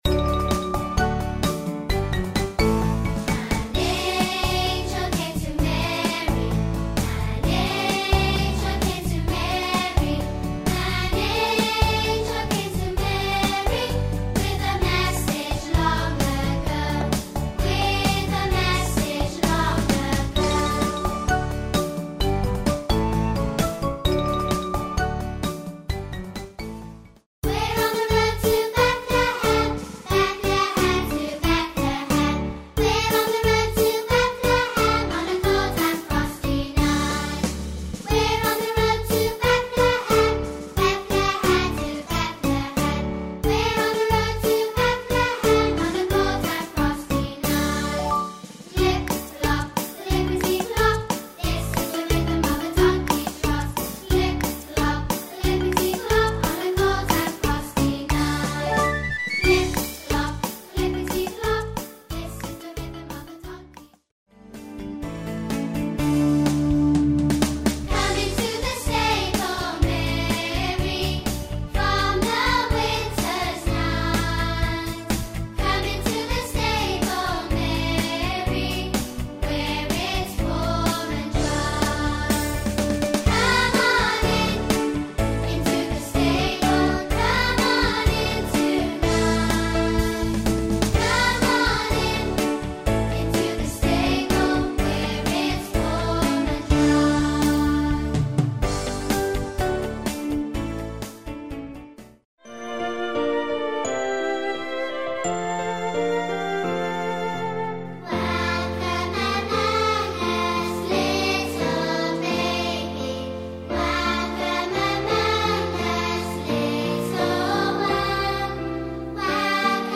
With simple, catchy tunes and repetitive lyrics